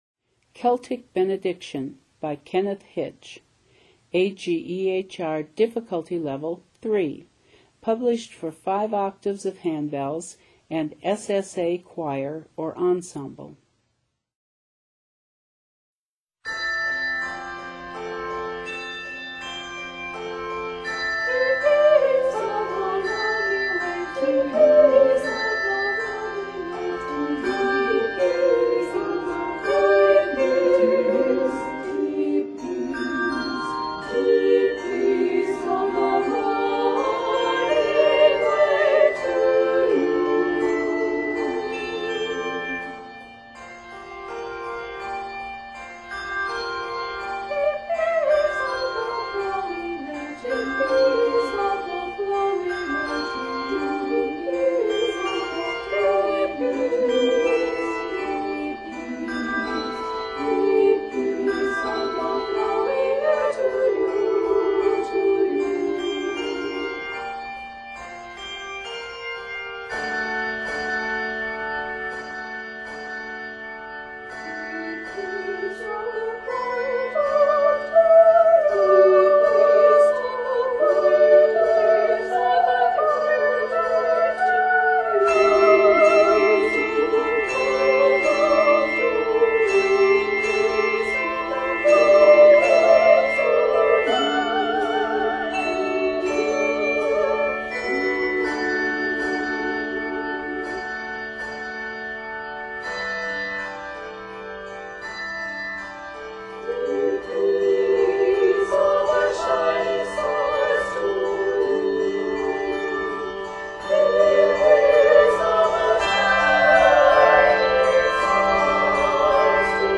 Scored in F Major, this work is 85 measures.